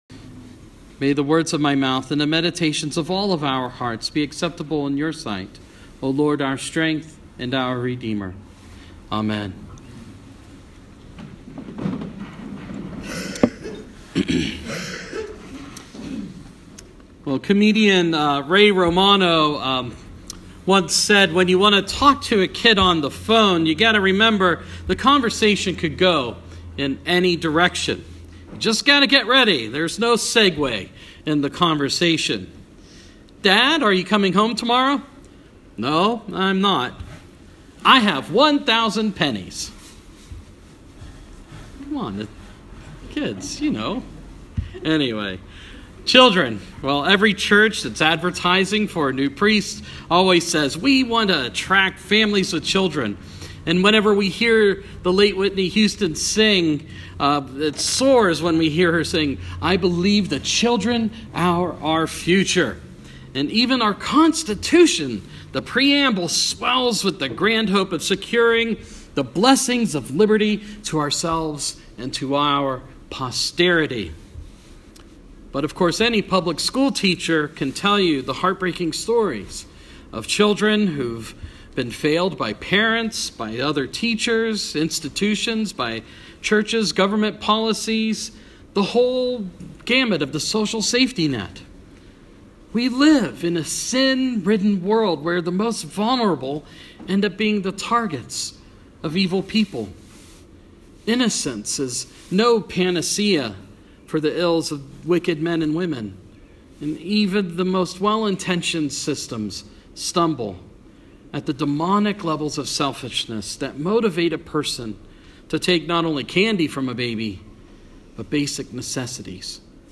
Sermon – Life of Christ – Blessing of the Children